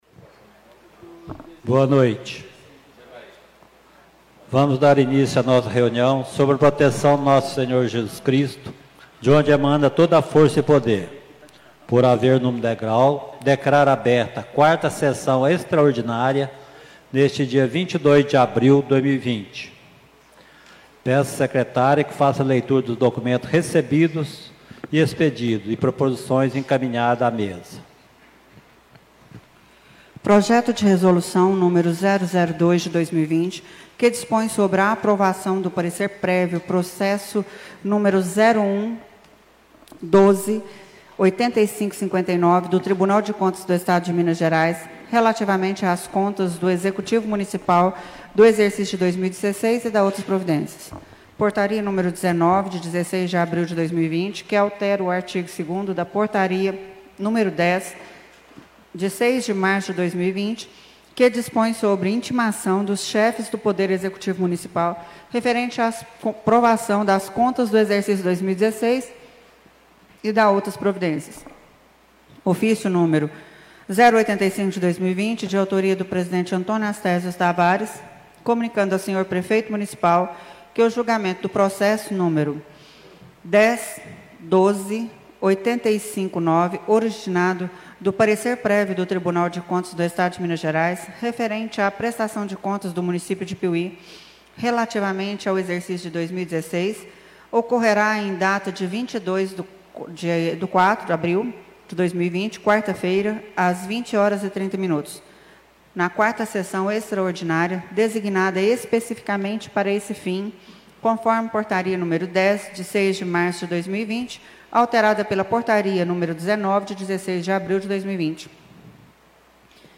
4ª Extraordinária de 2020 da 4ª Sessão Legislativa da 18ª Legislatura